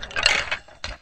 PixelPerfectionCE/assets/minecraft/sounds/mob/skeleton/step3.ogg at mc116